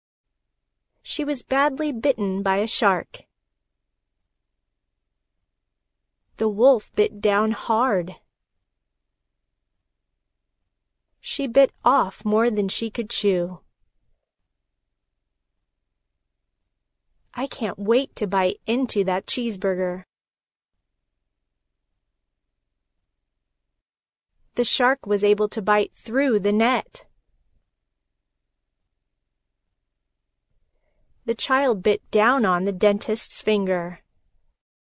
bite-pause.mp3